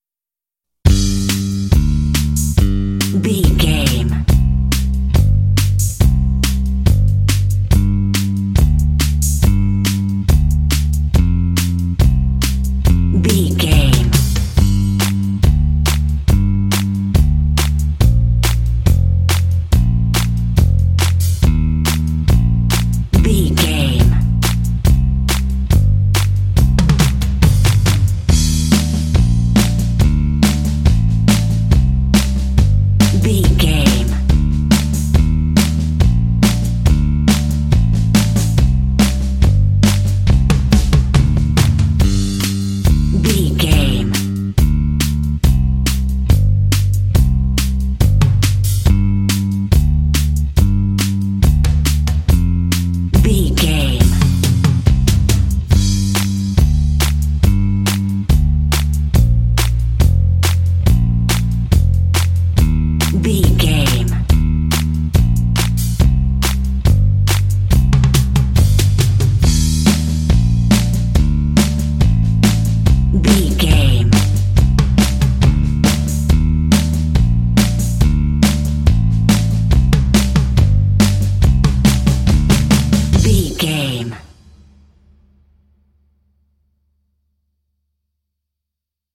Aeolian/Minor
smooth
calm
groovy
drums
bass guitar
indie
contemporary underscore
country